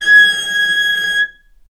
healing-soundscapes/Sound Banks/HSS_OP_Pack/Strings/cello/ord/vc-G#6-ff.AIF at 48f255e0b41e8171d9280be2389d1ef0a439d660
vc-G#6-ff.AIF